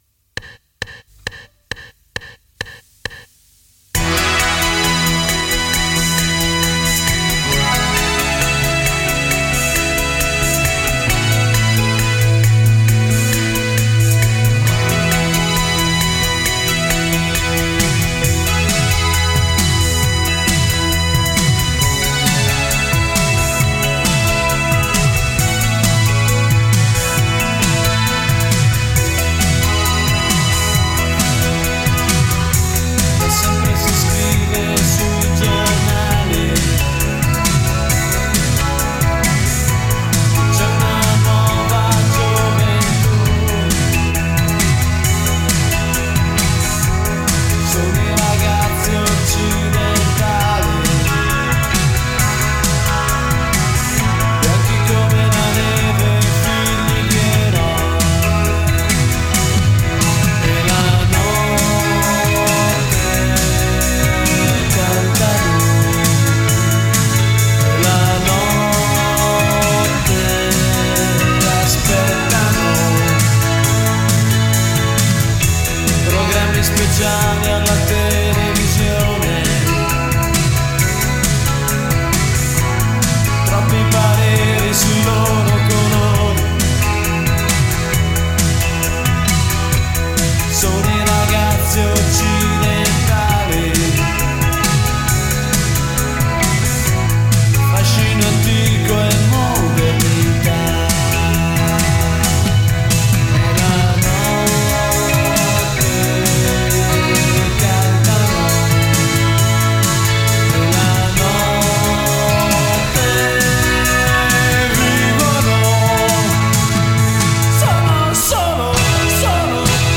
due gradevoli techno-pop in perfetto stile anni ’80